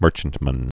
(mûrchənt-mən)